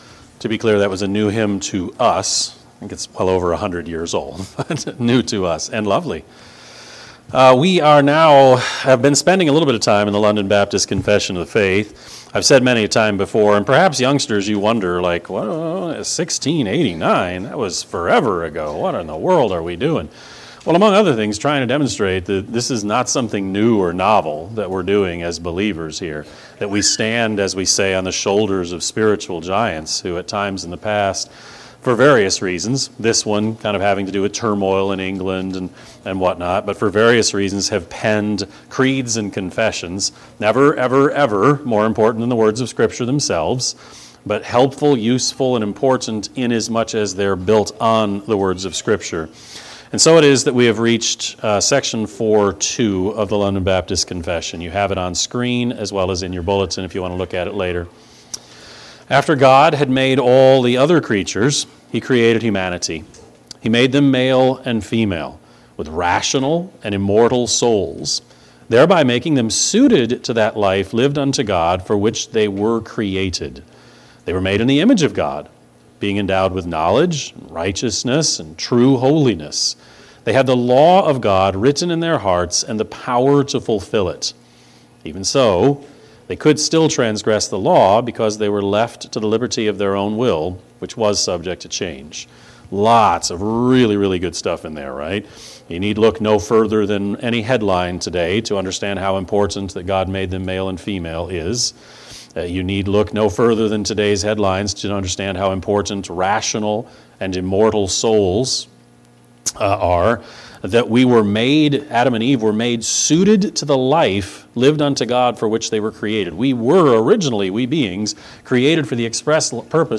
Sermon-6-1-25-Edit.mp3